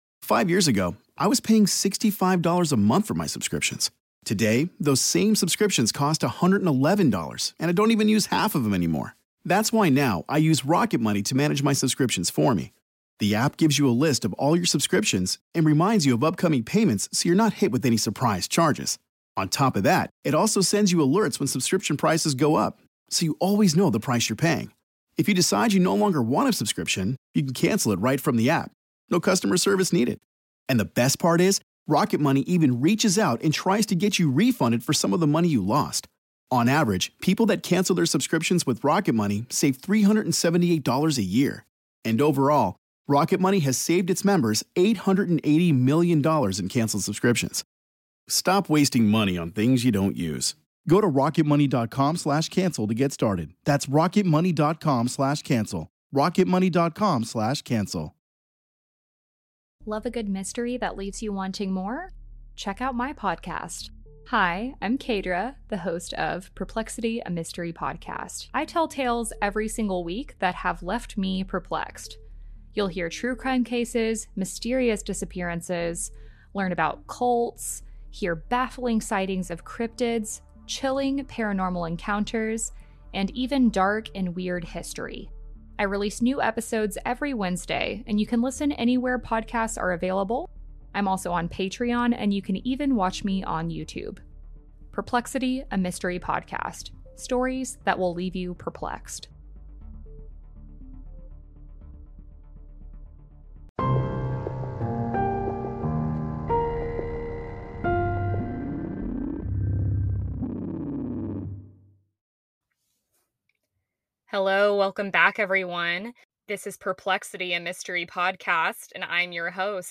podcasting in a closet. She covers her first ever listener request, the story of Cullen Davis.